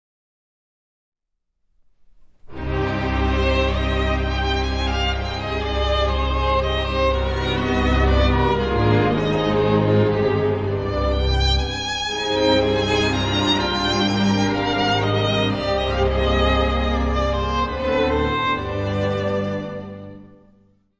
: stereo; 12 cm
Área:  Música Clássica
for violin and string orchestra